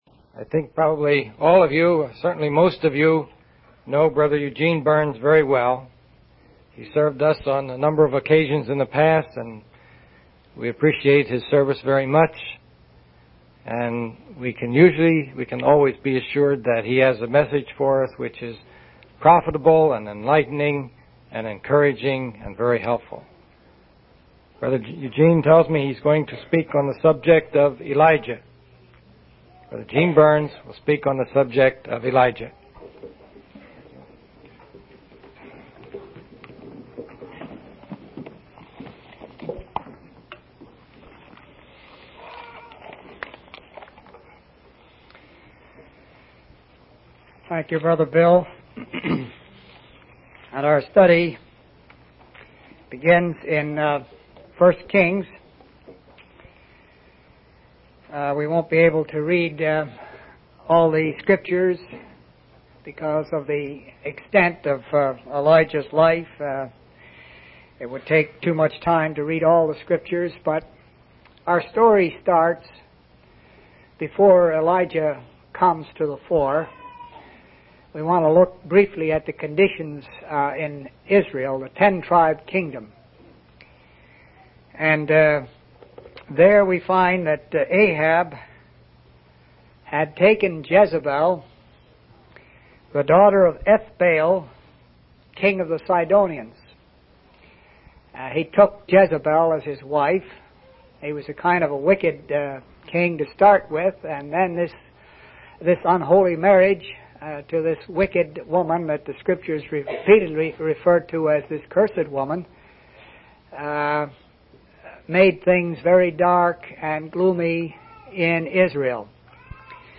From Type: "Discourse"
Phoenixville PA Convention 1978